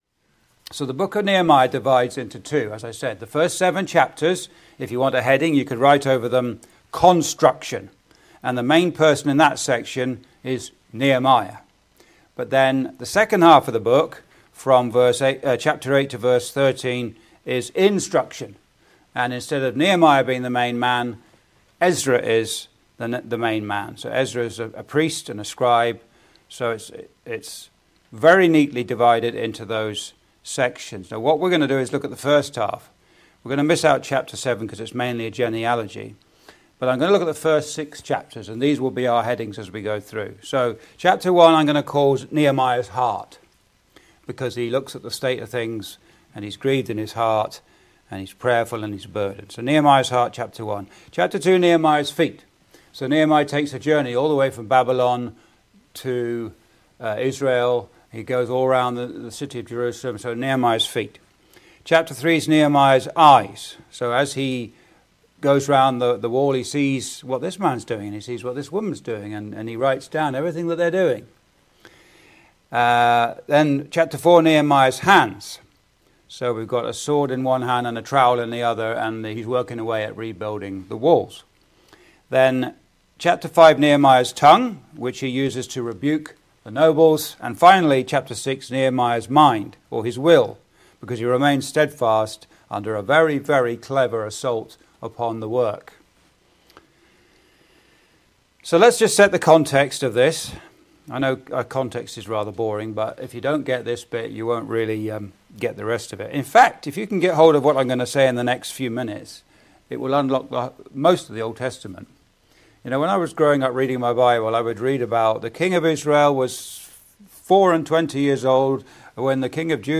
(Recorded in The Malden Road Gospel Hall, Windsor, ON, Canada on 10th Jan 2026)